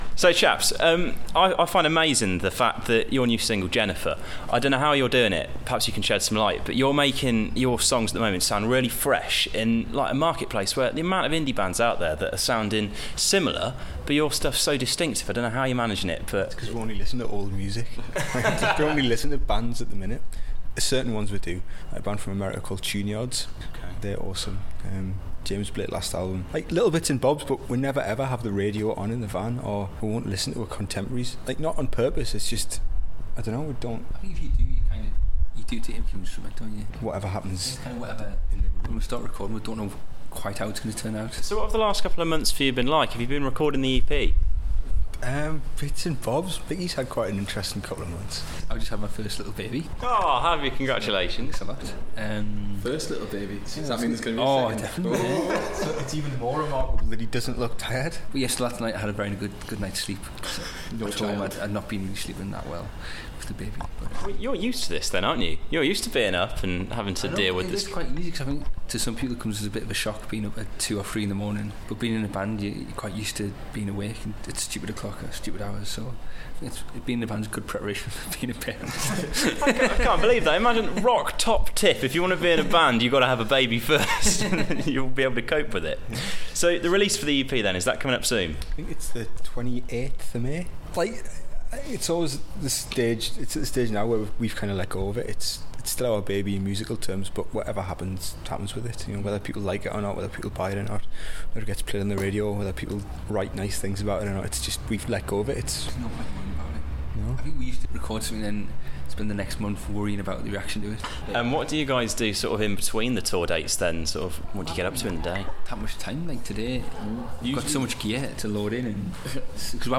The Source fumbled it's way up the incredibly steep spiral staircase to the dressing room at Manchester Academy to sit down with a cuppa with Little Comets to talk about their latest projects.